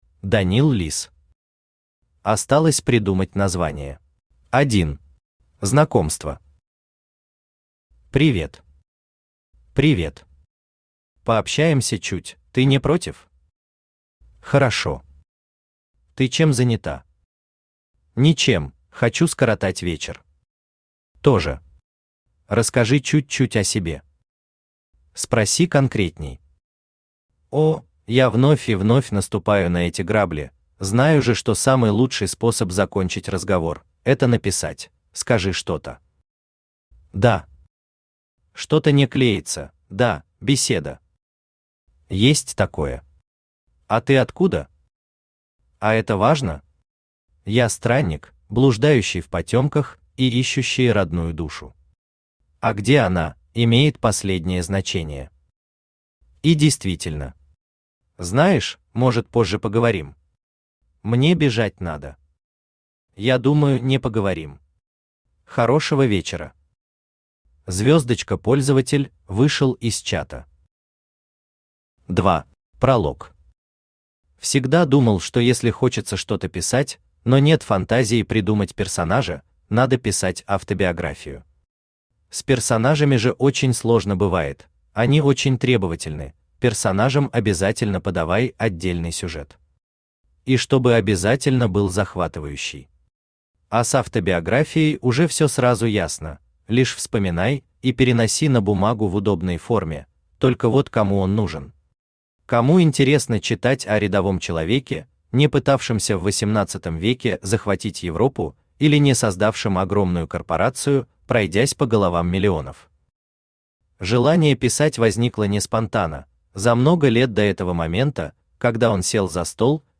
Аудиокнига Осталось придумать название | Библиотека аудиокниг